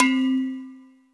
AFFRBELLC3-L.wav